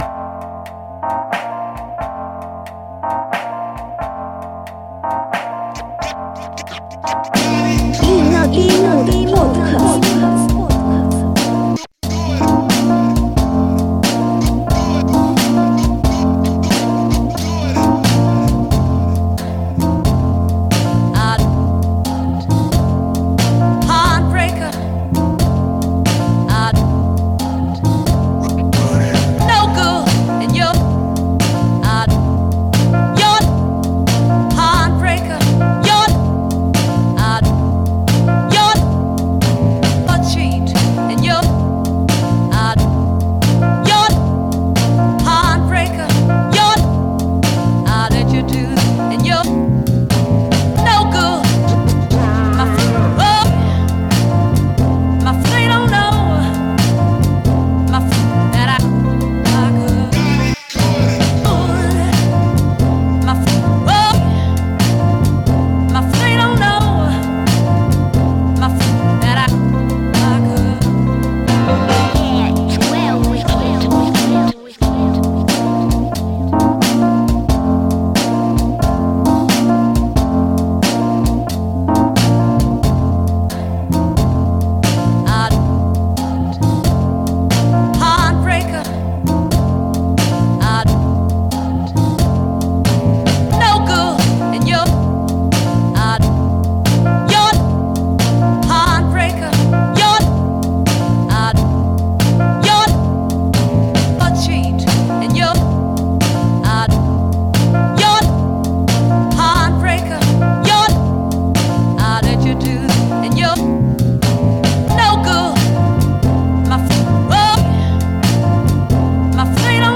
full of beautiful voices and reworked instrumentals